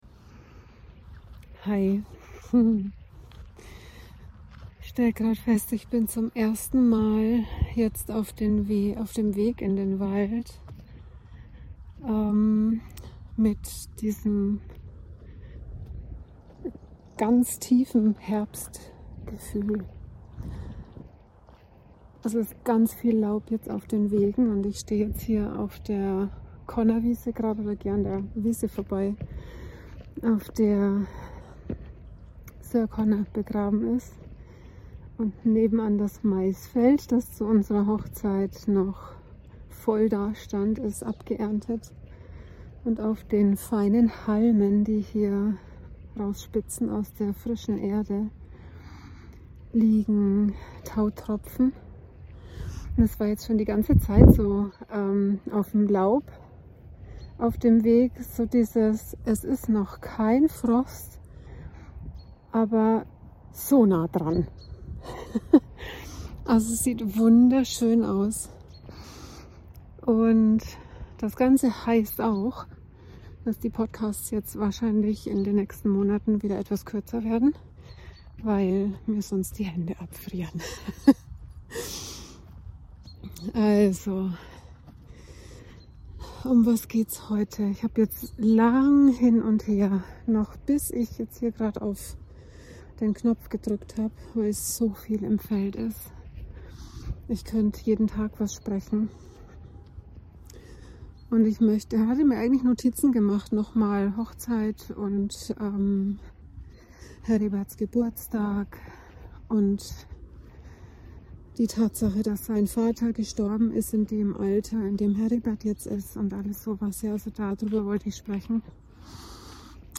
Es ist immenser Widerstand in den Frauen an diesem Punkt. es ist die Lösung \ Gesprochen im HerbstWald. Achte auf das ‚Auffliegen‘ und die Spinnwebe.